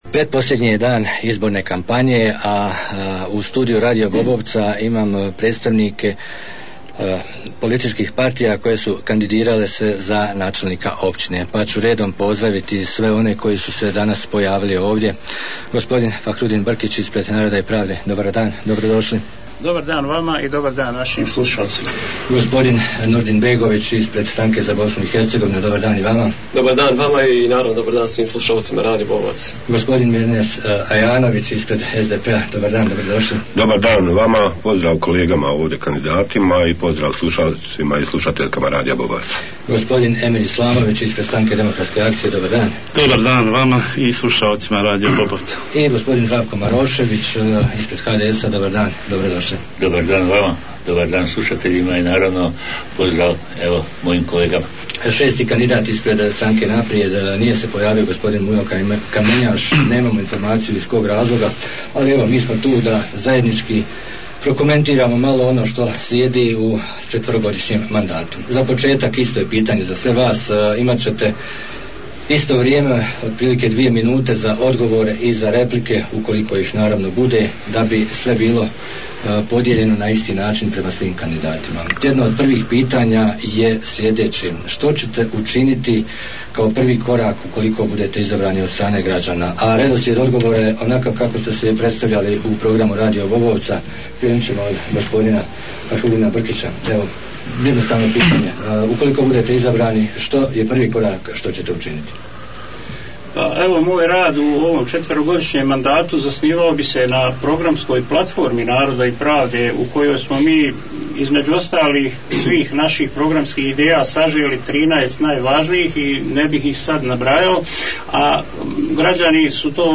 Debatna emisija s kandidatima za načelnika Općine Vareš
U studiju radio Bobovca ugostili smo predstavnike pet političkih subjekata koji su se kandidirali za poziciju načelnika Općine Vareš, poslušajte debatnu emisiju.....